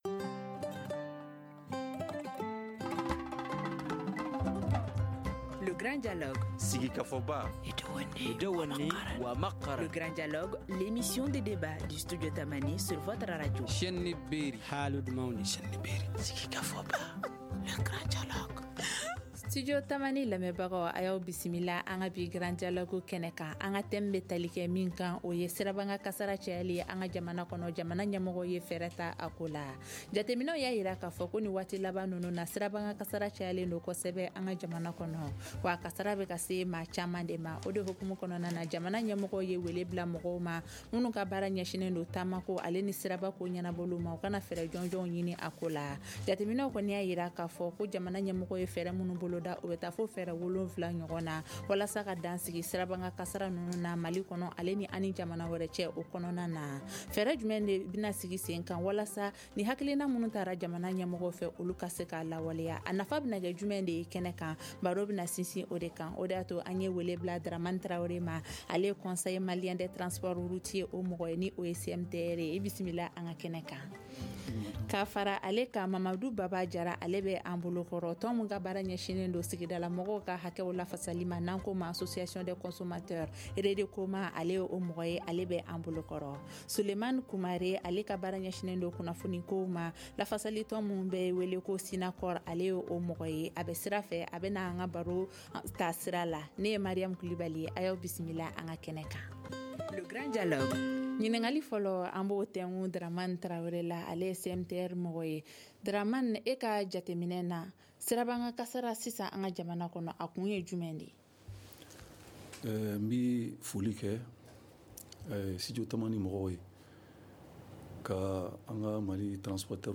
Elles ont annoncé sept mesures pour mettre fin à ces accidents mortels sur les axes routiers interurbains ou internationaux. Quel peut être l’impact de ces mesures sur l’insécurité routière? Seront-elles appliquées correctement ?Le débat d’aujourd’hui s’y intéresse.